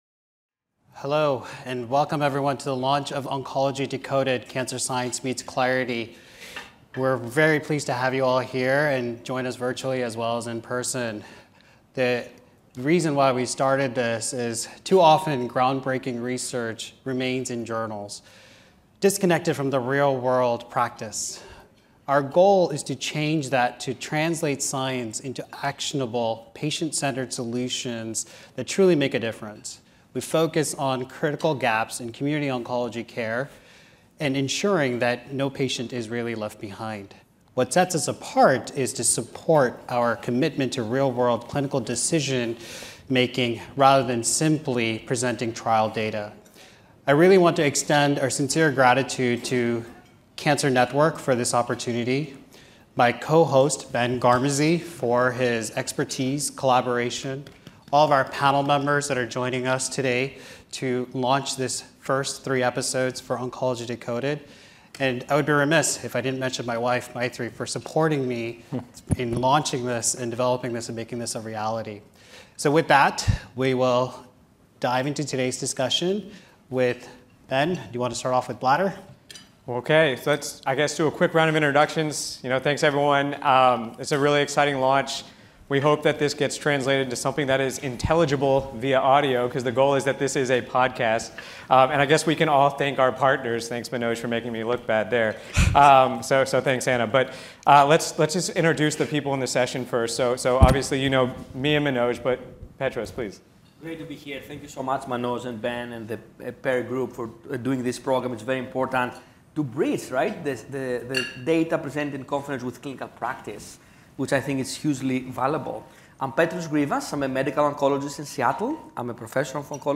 During the 2025 ASCO Genitourinary Cancers Symposium, Oncology Decoded held their inaugural podcast with a live filming taking place at the conference.